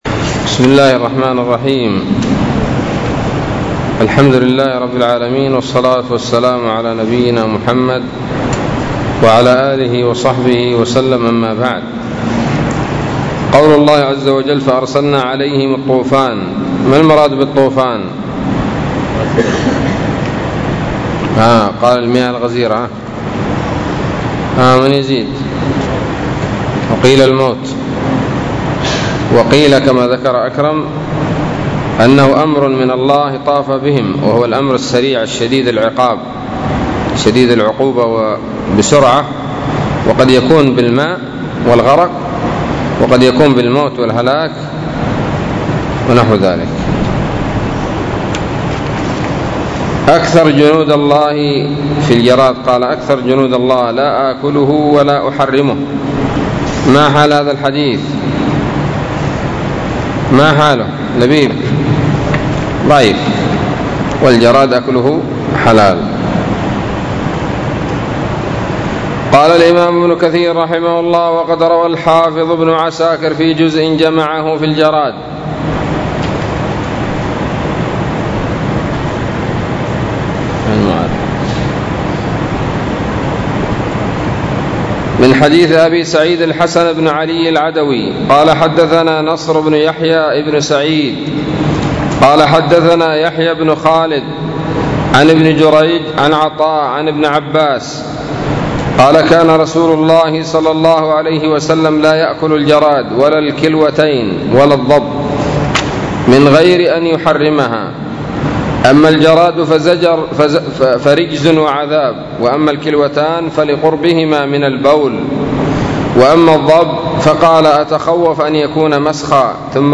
الدرس الثاني والأربعون من سورة الأعراف من تفسير ابن كثير رحمه الله تعالى